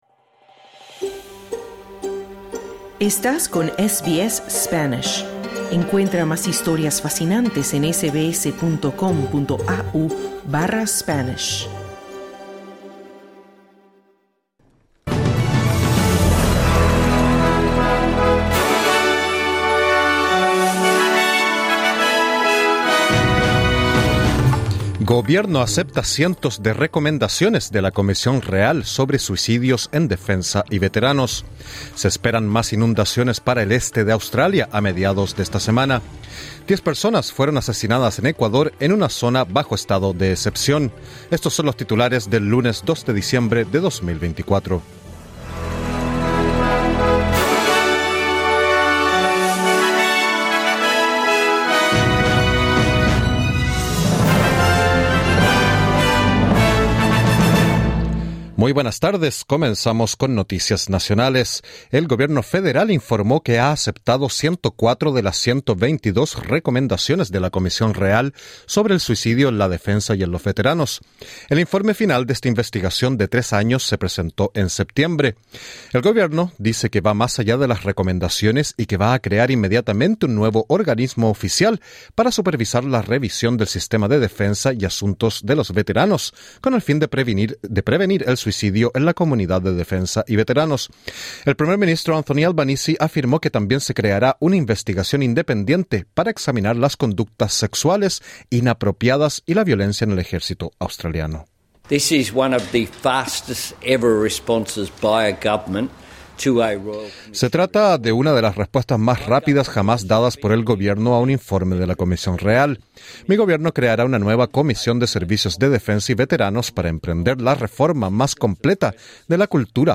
Se esperan más inundaciones para el este de Australia a mediados de esta semana. Diez personas fueron asesinadas en Ecuador en una zona bajo estado de excepción. Escucha el boletín en el podcast localizado en la parte superior de esta página.